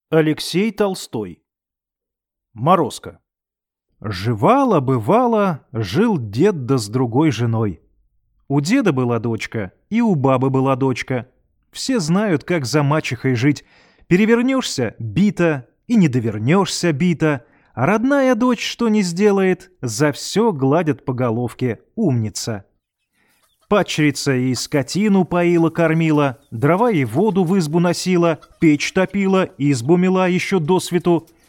Aудиокнига Морозко